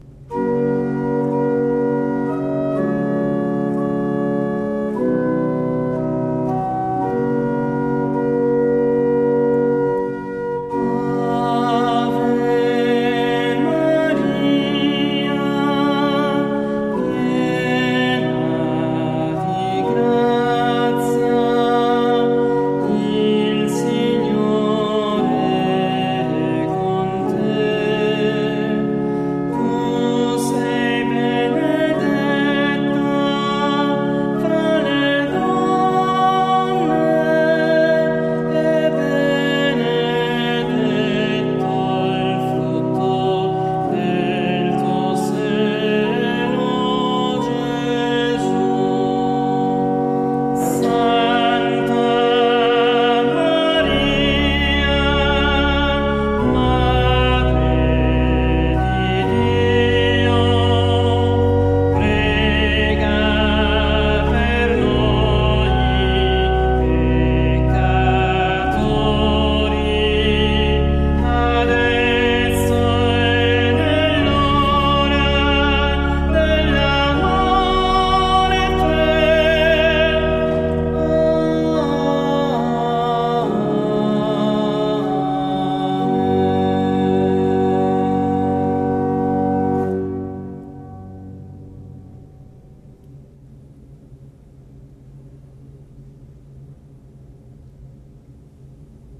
All'organo Agati
organista e solista